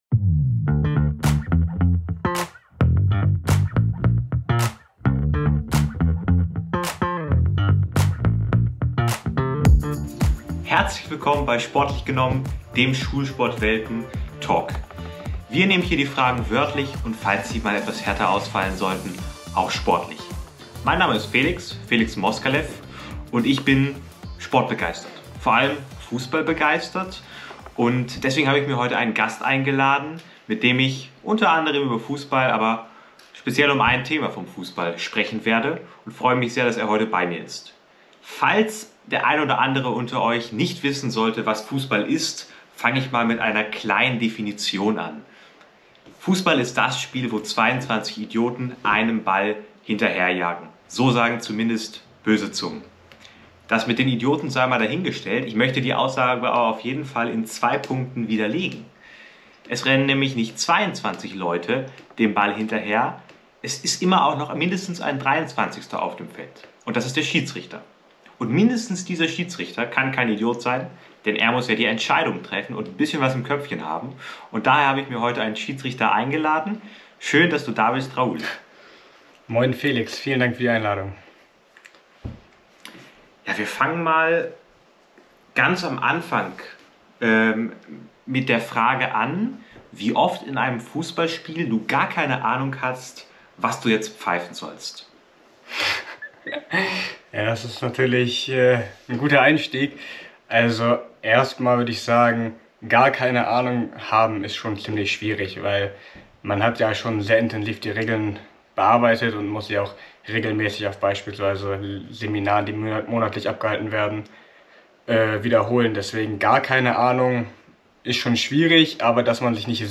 Freut euch also auf unseren Video-Talk bei SchulSportWelten und das Eintauchen in reizvolle Themenfelder.